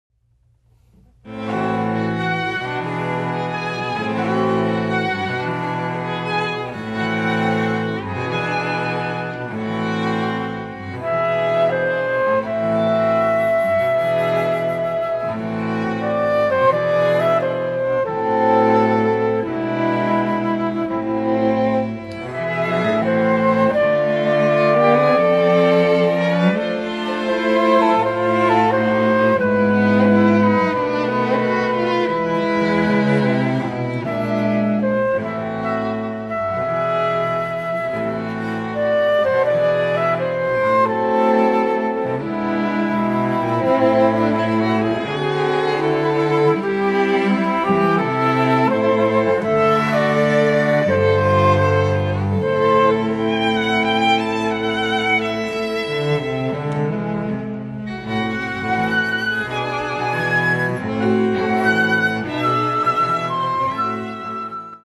FLUTE QUARTET
(Flute, Violin, Viola and Cello)
or STRING QUARTET
MIDI